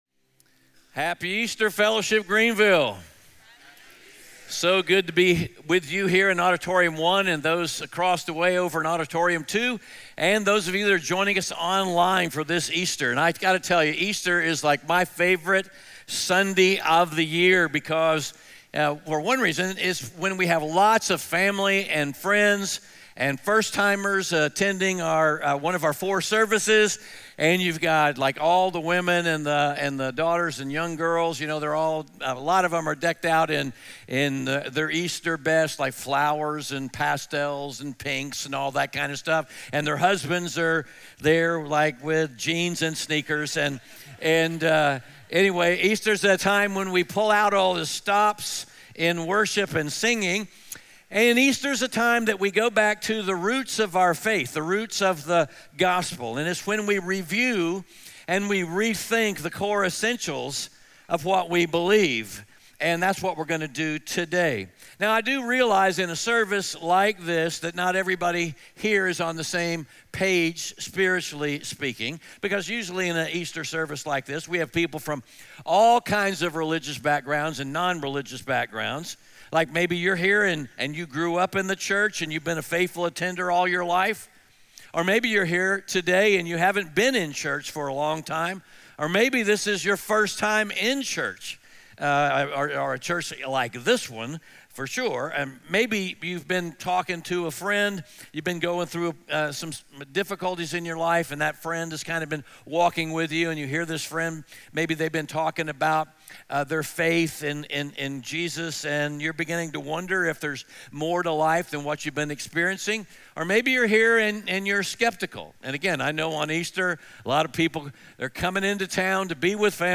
Luke 24:1-12 Audio Sermon Notes (PDF) Ask a Question Scripture: Luke 24:1-12 SERMON SUMMARY The Easter Gospel—the core idea of our faith—revolves around two questions: Why did Jesus have to die on the Cross?